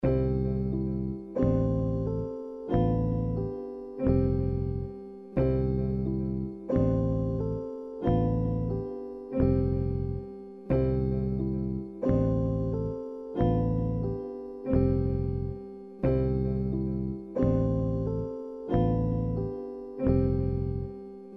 描述：8个小节的寒风循环。贝斯、E型钢琴和古特吉他